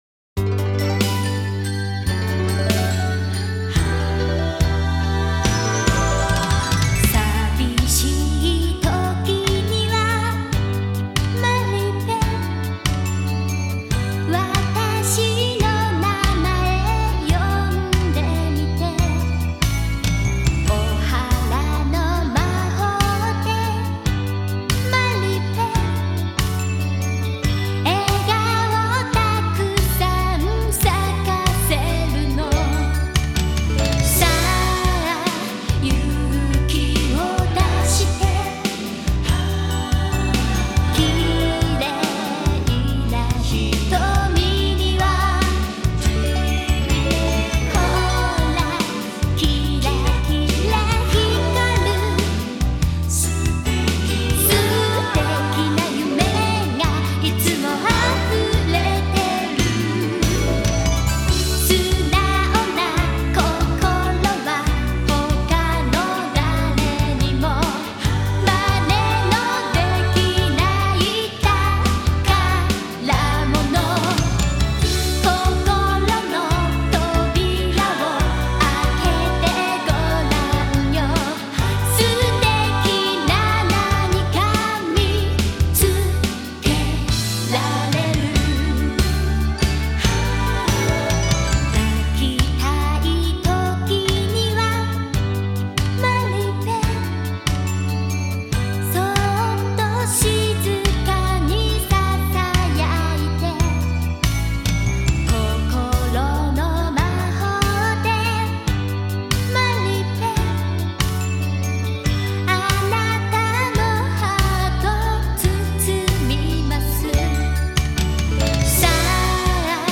The music is very uplifting and soothing.